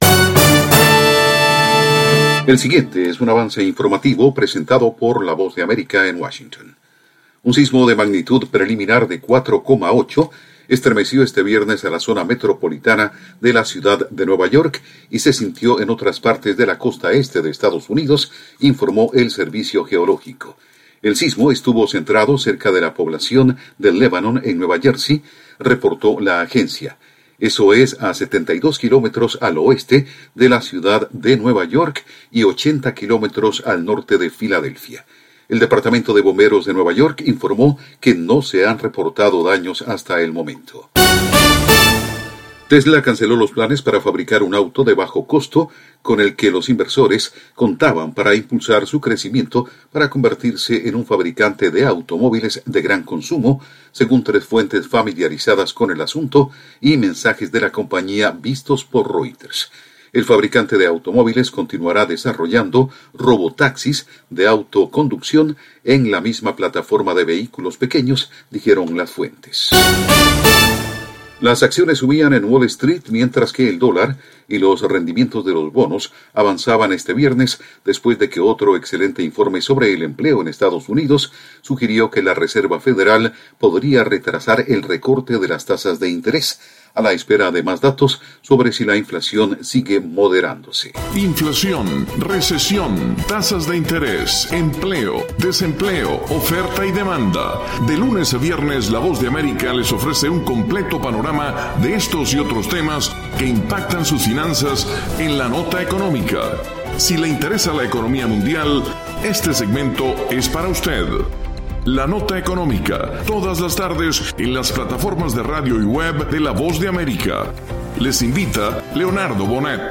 El siguiente es un avance informativo presentado por la Voz de America en Washington